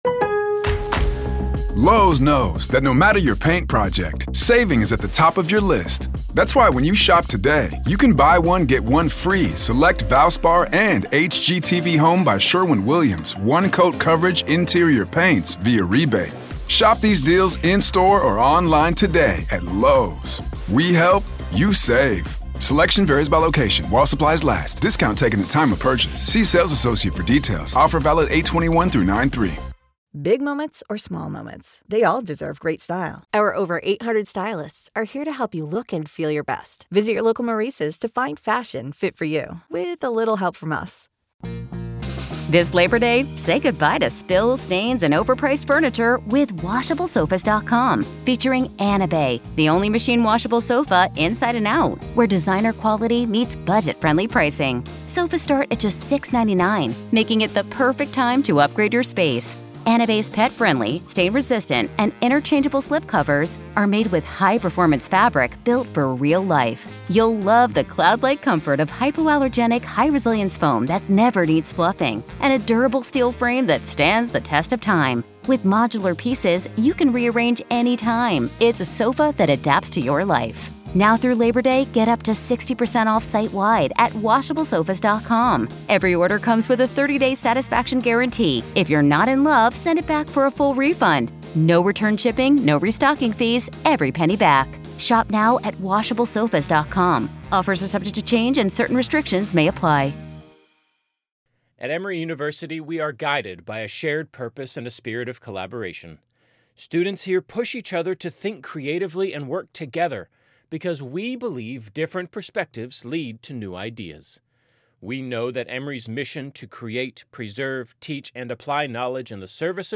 Court Audio